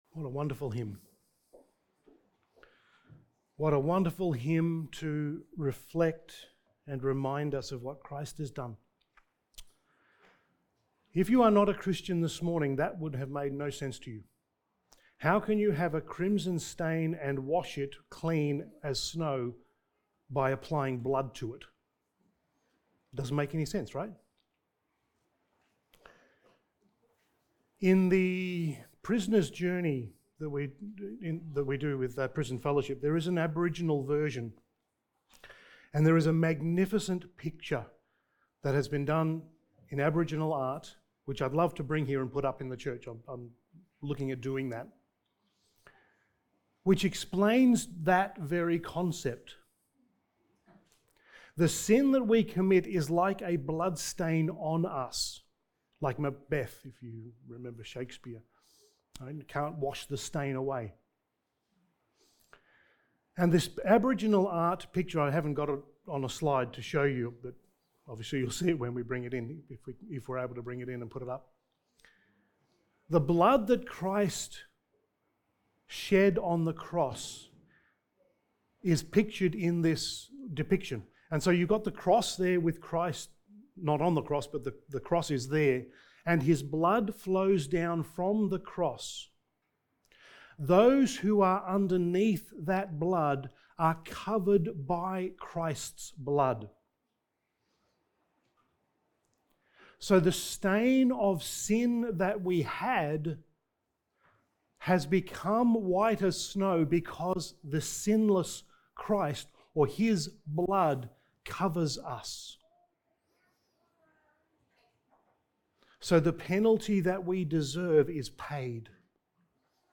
Passage: Acts 27:27-28:16 Service Type: Sunday Morning